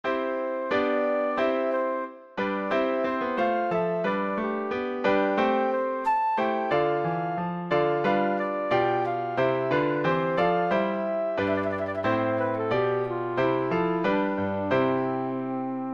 Přednesová skladba pro zobcovou flétnu